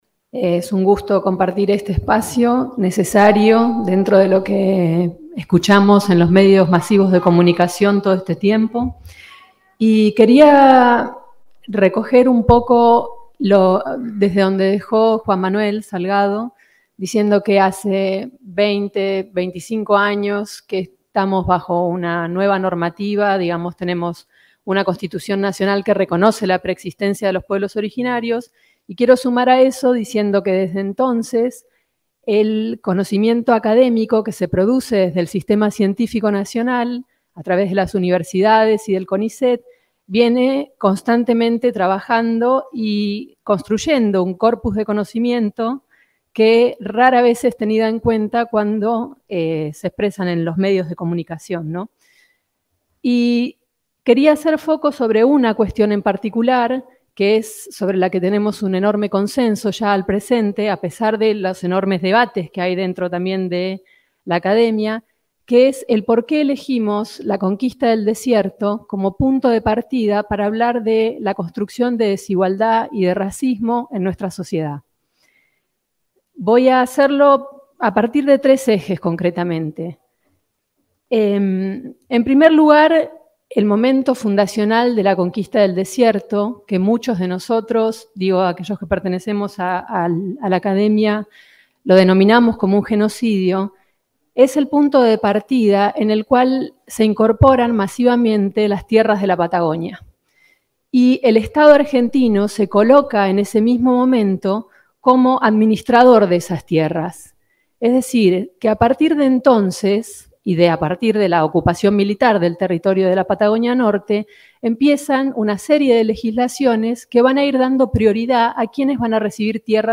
expuso como panelista en el Encuentro Intercultural: territorio, derecho y pueblos originarios que se realizó en Bariloche el 1 de noviembre.